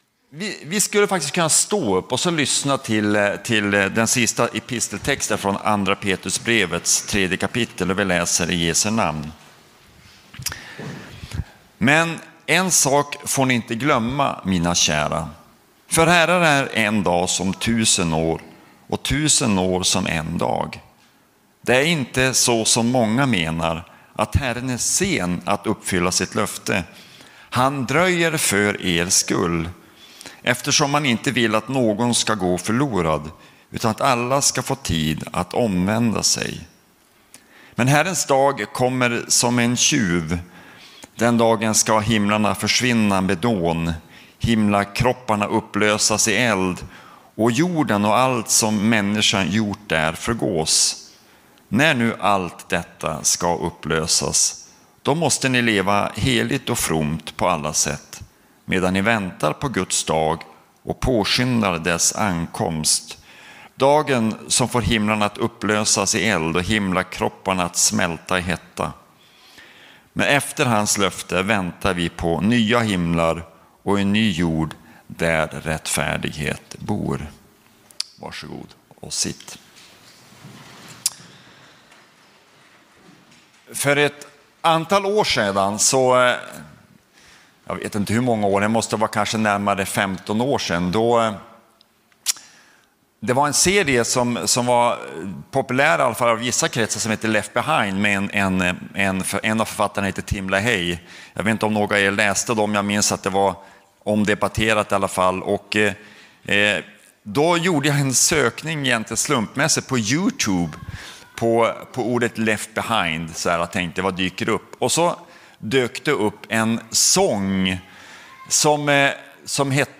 Predikningar från Centrumkyrkan Mariannelund